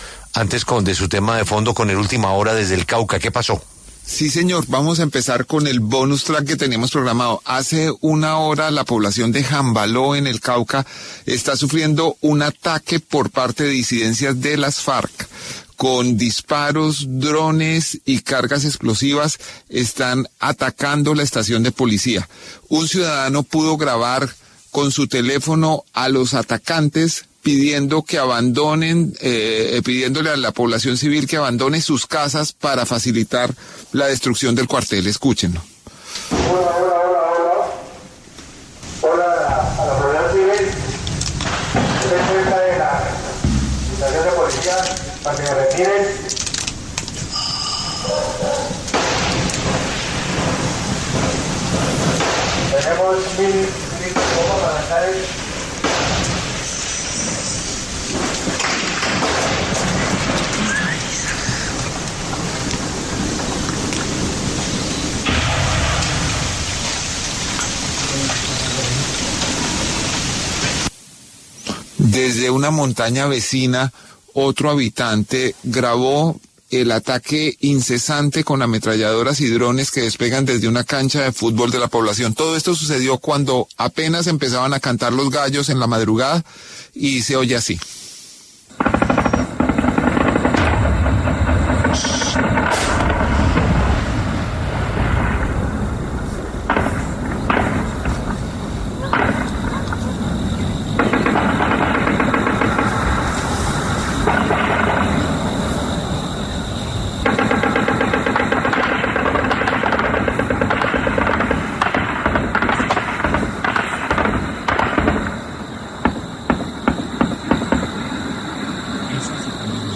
El Reporte Coronell muestra el ataque con fusiles que sufre la población de Jambaló, Cauca.
Con disparos, drones y cargas explosivas están atacando la estación de Policía.
Un ciudadano pudo grabar con su teléfono a los atacantes pidiendo que abandonen sus casas para facilitar la destrucción del cuartel.